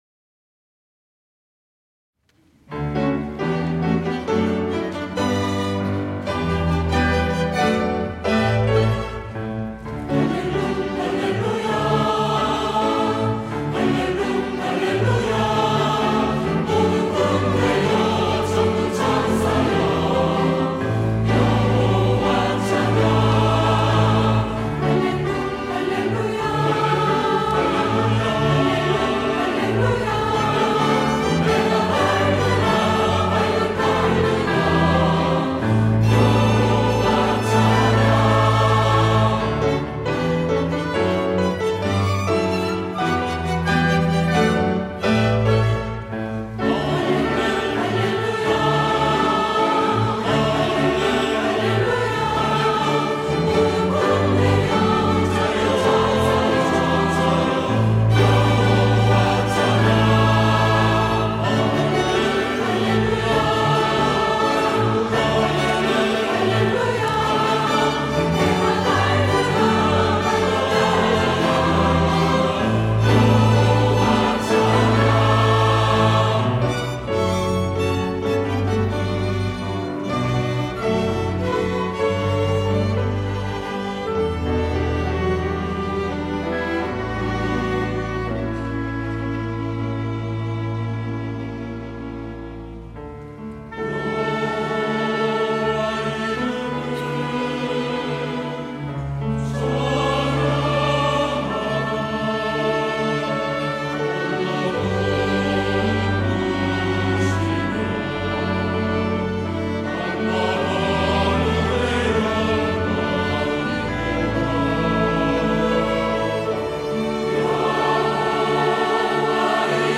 호산나(주일3부) - 할렐루 할렐루야
찬양대